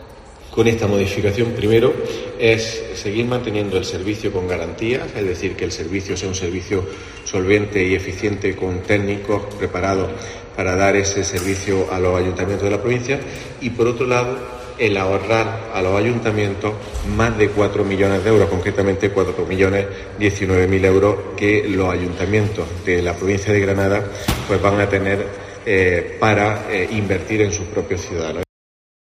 Nicolás Navarro, Portavoz del Gobierno de la Diputación